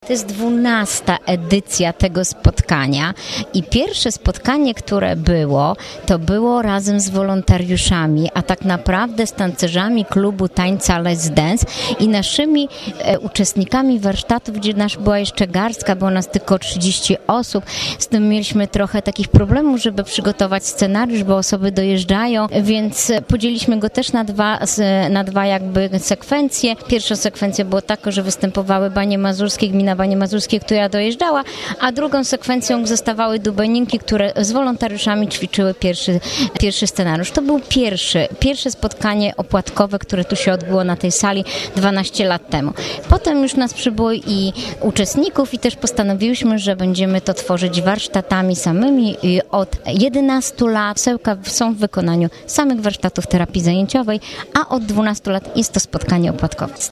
Odbyło się ono w sali widowiskowej Domu Kultury.
o historii spotkań mówi